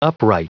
Prononciation du mot upright en anglais (fichier audio)